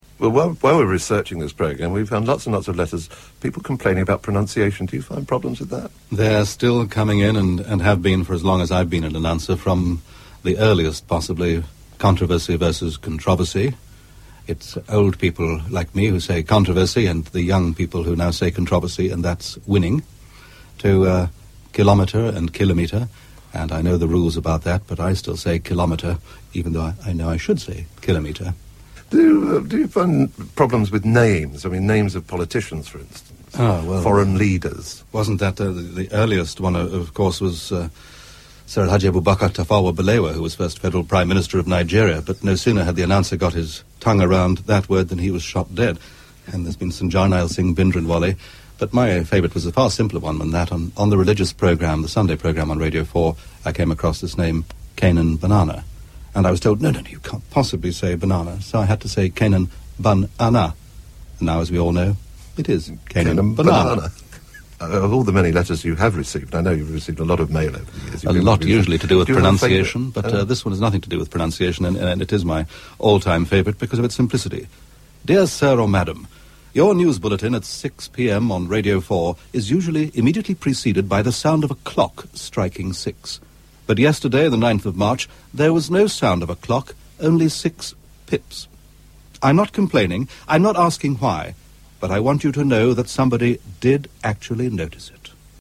Former BBC Radio 4 announcer talking to Barry Took on Radio 2 in 1997.